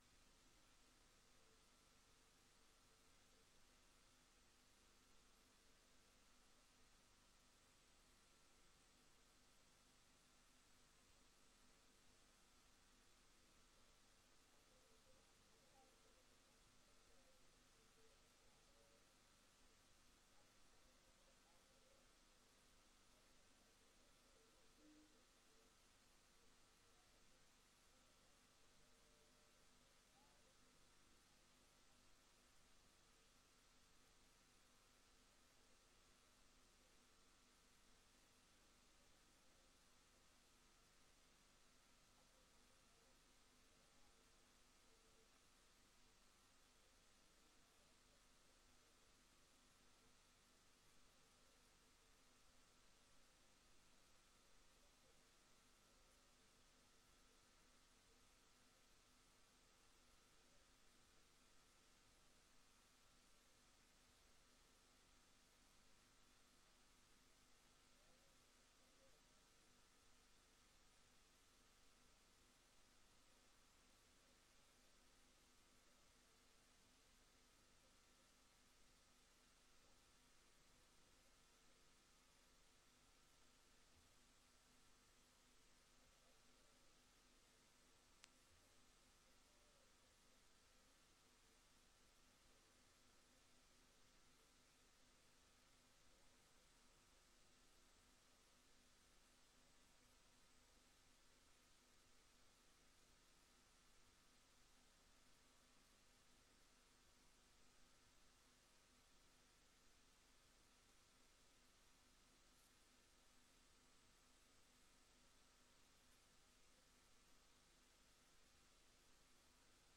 Oordeelsvormende vergadering Papendrecht 30 november 2023 20:00:00, Gemeente Papendrecht